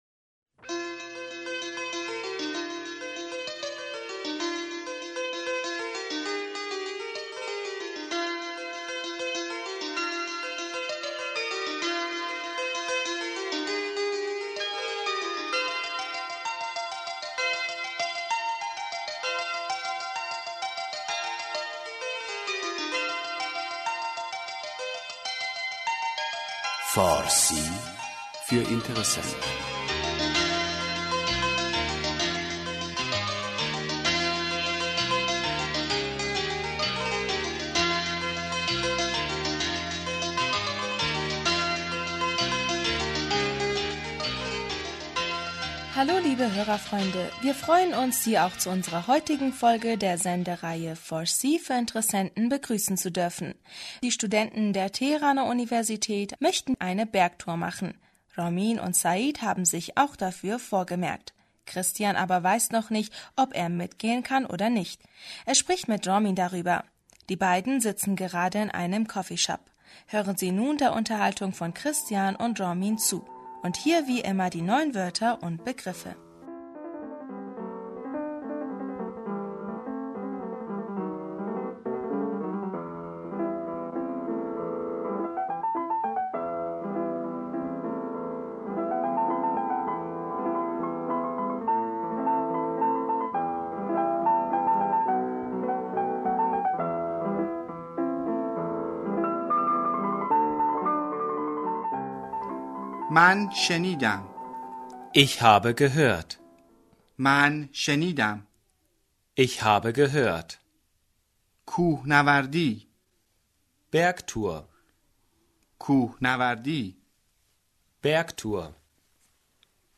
Hören Sie nun der Unterhaltung von Christian und Ramin zu.
(Zweimal lesen und wiederholen) Ich habe gehört man schenidam من شنیدم Bergtour kuhnawardi کوهنوردی Ist das richtig? doroste?